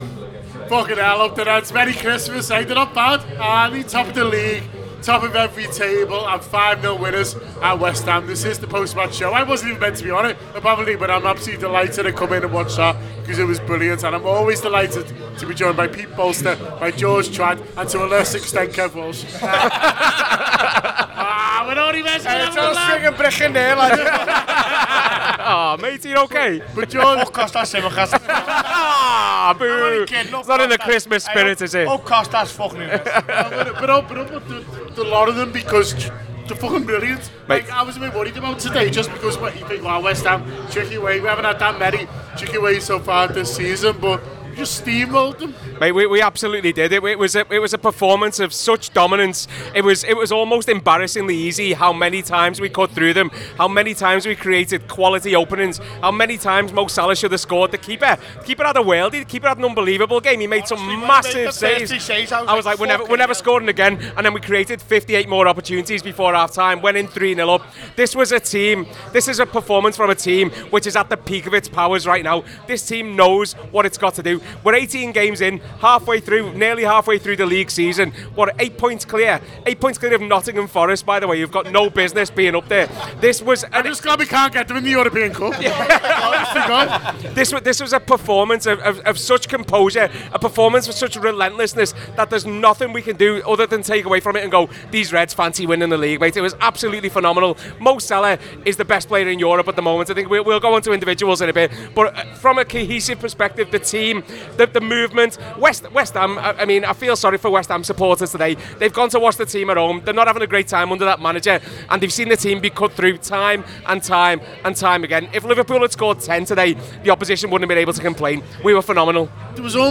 The Anfield Wrap’s post-match reaction podcast after West Ham 0 Liverpool 5 at the London Stadium.